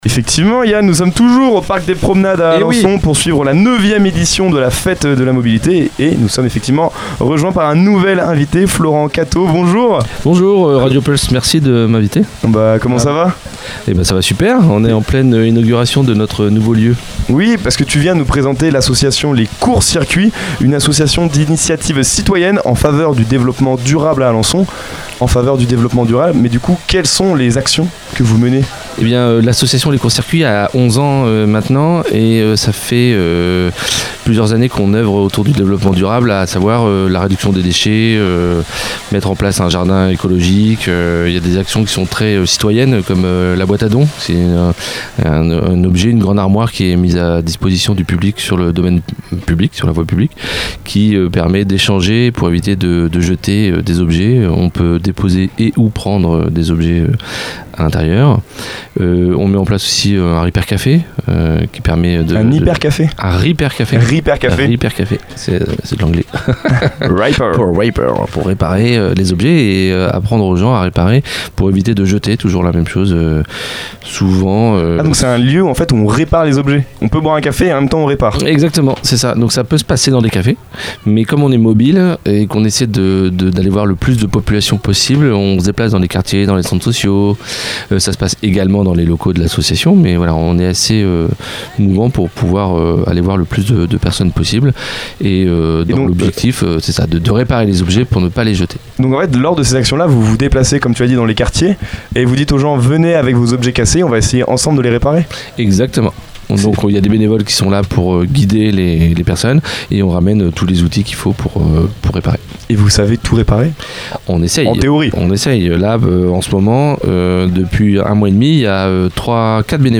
À l’occasion de la Fête de la Mobilité au Parc des Promenades à Alençon, nous étions en direct depuis la Mystery Machine (studio radio aménagé dans une camionnette).